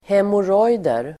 Uttal: [hemor'åj:der]